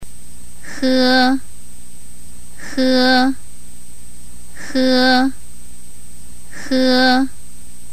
下記３つの子音は発声練習には[ e ]をつけて「 ge 」、「 ke 」、「he 」で練習します。
中国語の子音 日本語の「フ」で代用可能。
厳密には日本語の「フ」を喉の奥から出す感じ。
he1.mp3